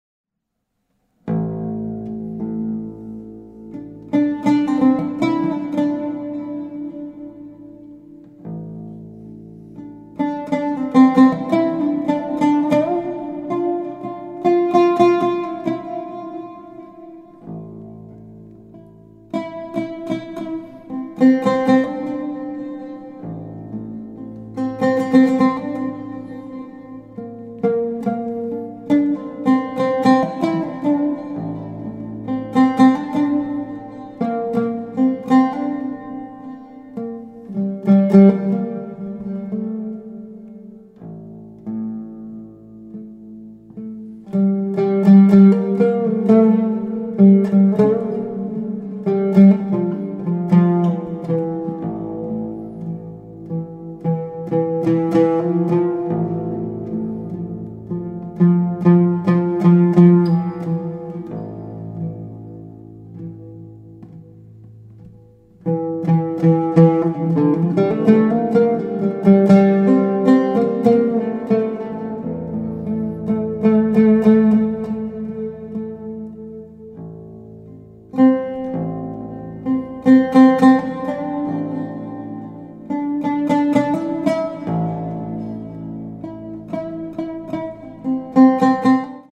Contemporary , Japanese Ambience
, Oud , Relaxing / Meditative